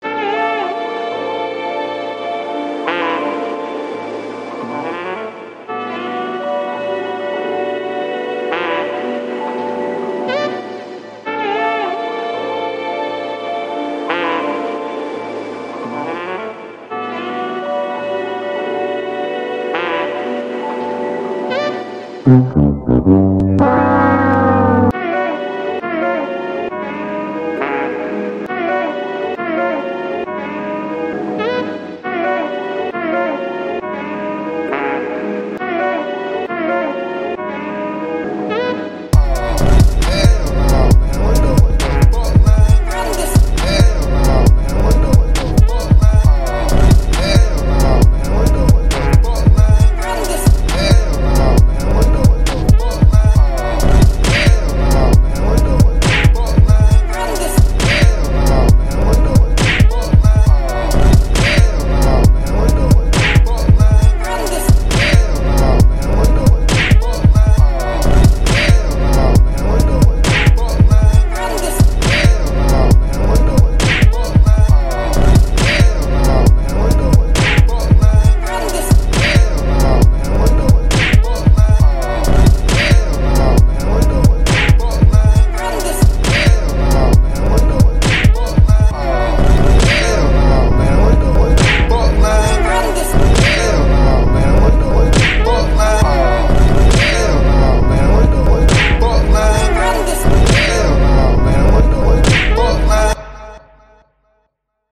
Music / Rap
hip hop trap remix instrumental
jazz sample
The producer was supposed to cook, but he ordered takeout, recorded everyone’s reactions, then sampled it and made a beat out of it.